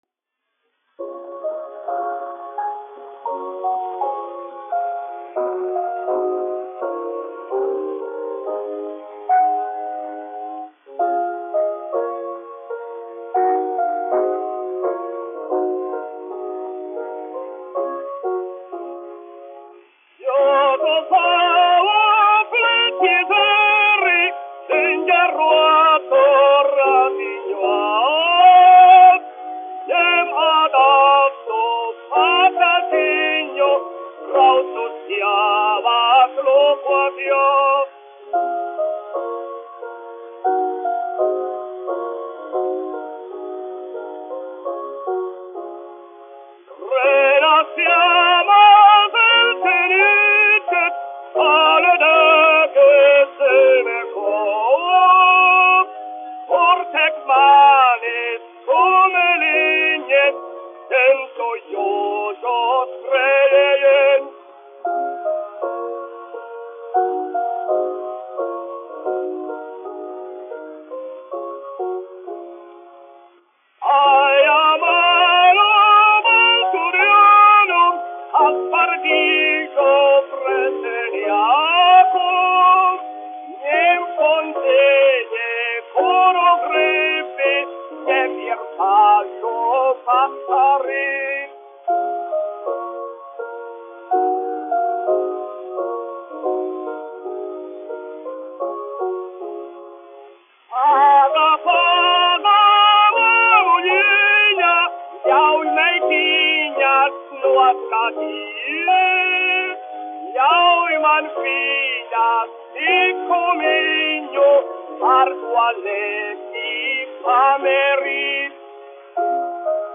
1 skpl. : analogs, 78 apgr/min, mono ; 25 cm
Skatuves mūzika--Latvija
Dziesmas (augsta balss) ar klavierēm
Skaņuplate